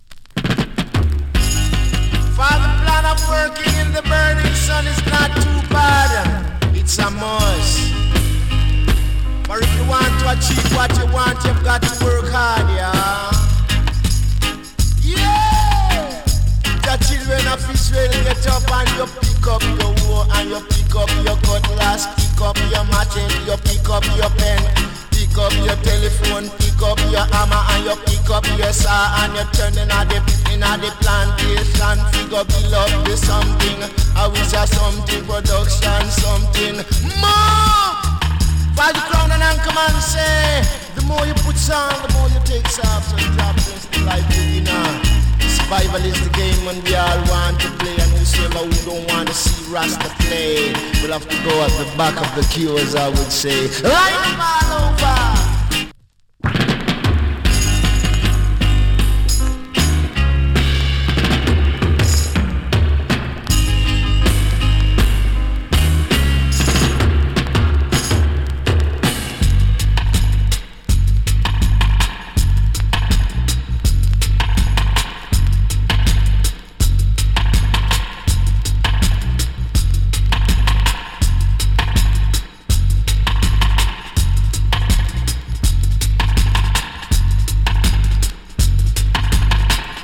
7inch
チリ、パチノイズ少し有り。